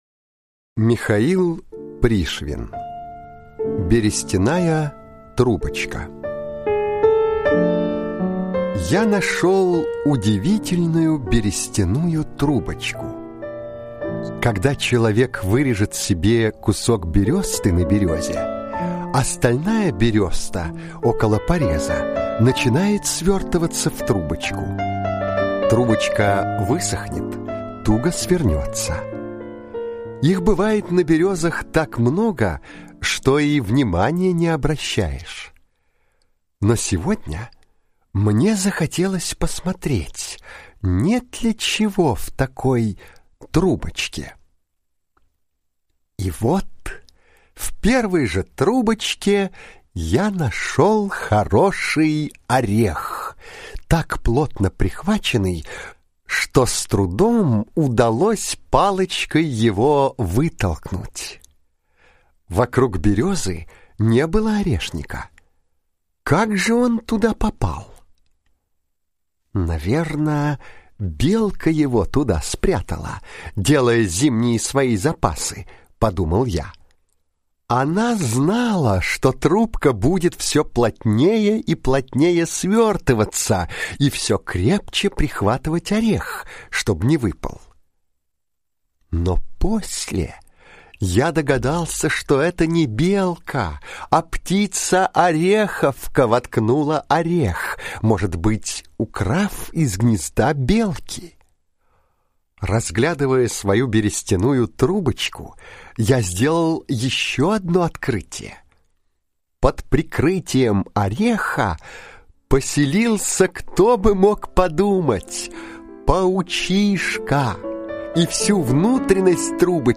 Берестяная трубочка – Пришвин М.М. (аудиоверсия)